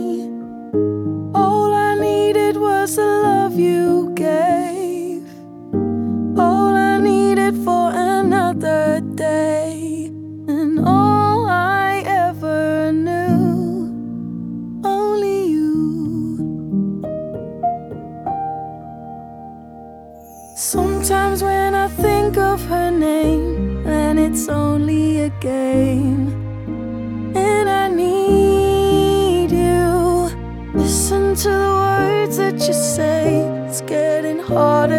Жанр: Танцевальная музыка